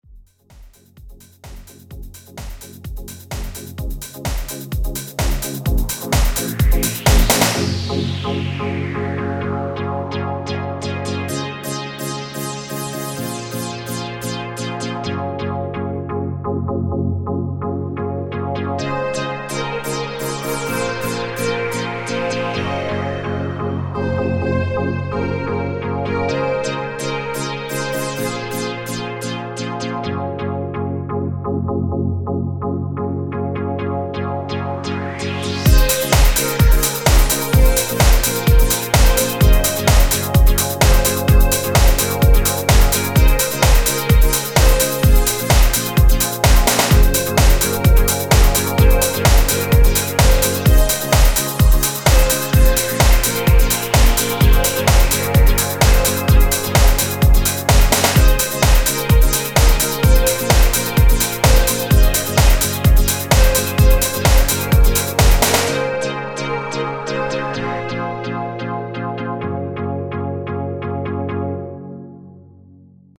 Basic trance practice
Trying to combine melodies and such..